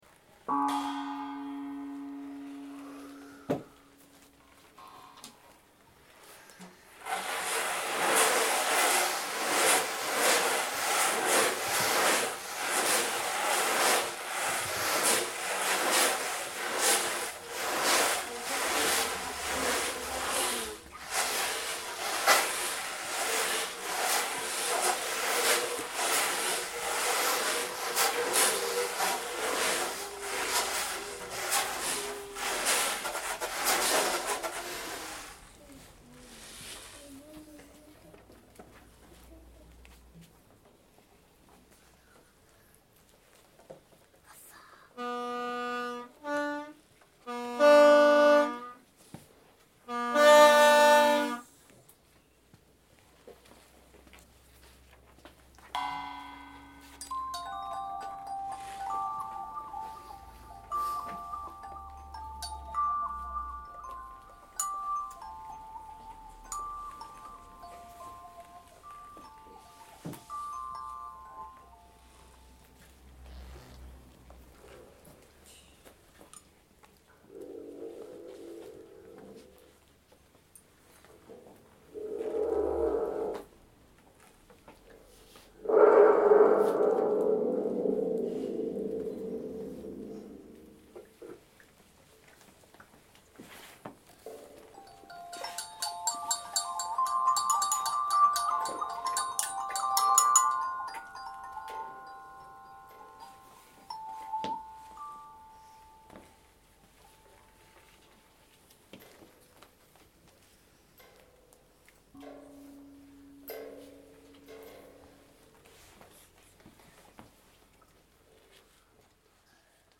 Puis nous avons inventé un ’univers musical’ racontant cette chanson.
Cet univers sonore, nous l’avons codé puis nous l’avons joué.
Nous avons tenu différents rôles au cours de ces séances : musiciens et chef d’orchestre.
Le tuyau harmonique qui rappelle le chant de la baleine.
Le tambour océan nous évoque les vagues.
Les cornes de brume font penser à un bateau.
Le son des wah- wah ressemble à celui d’une baleine qui pleure.
Les boîtes à tonnerre rappellent un orage ou un coup de fusil…